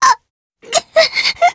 Selene-Vox_Dead_jp.wav